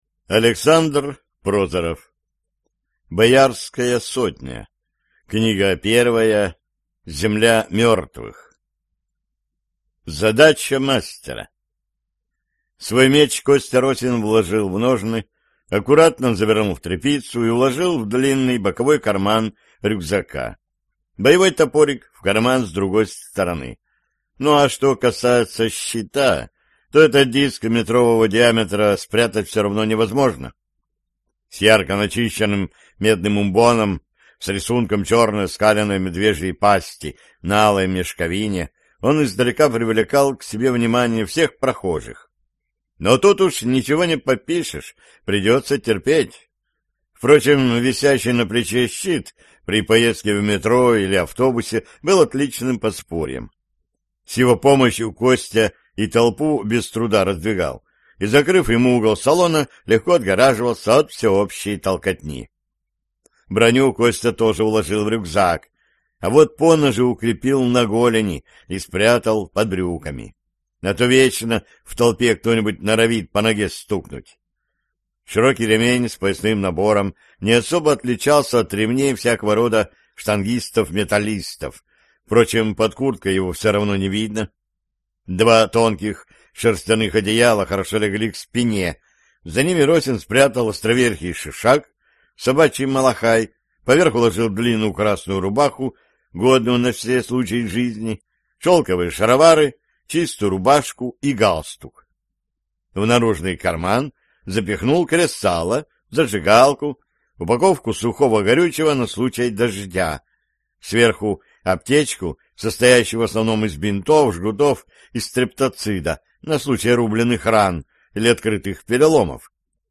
Аудиокнига Земля мертвых | Библиотека аудиокниг